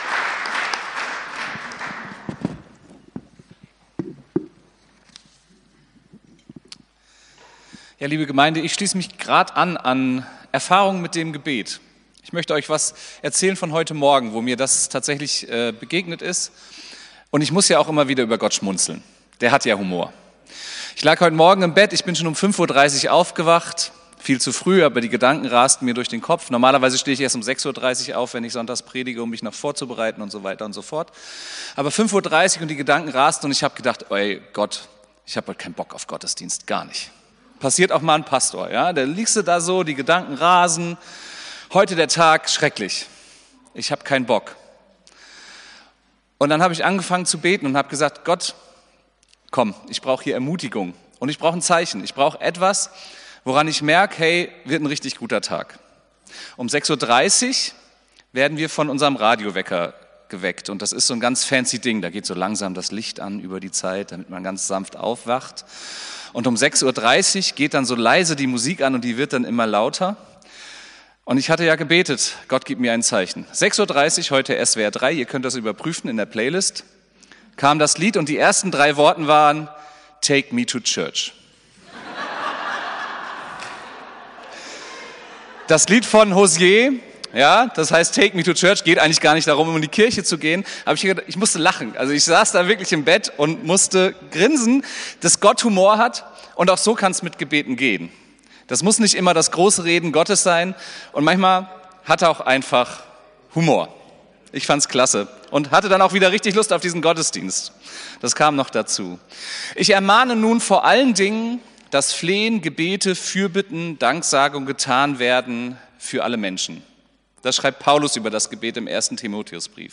Predigt vom 23.02.2025